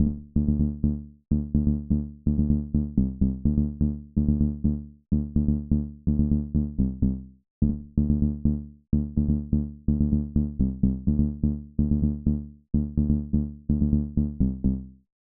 • Punchy Tech House Bass Rhythm - EDM - D - 126.wav
Punchy_Tech_House_Bass_Rhythm_-_EDM_-_D_-_126_5Z5.wav